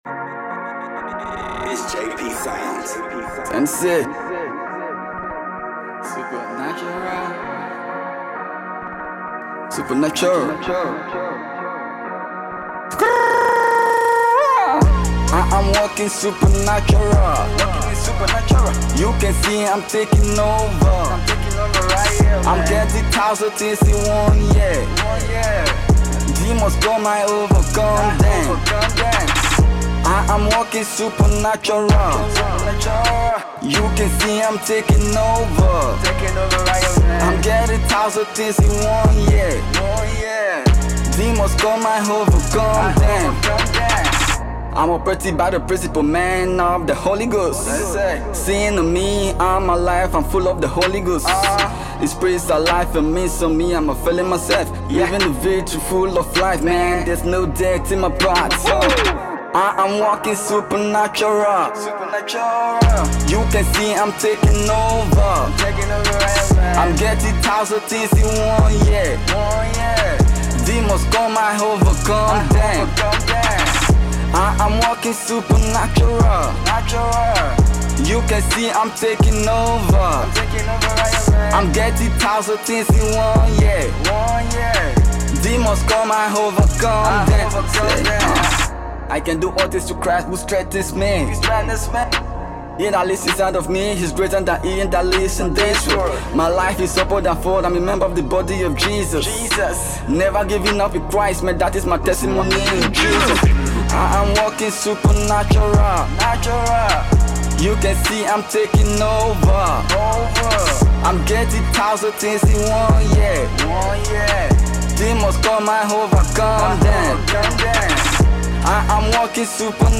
Christian Hip Hop Artiste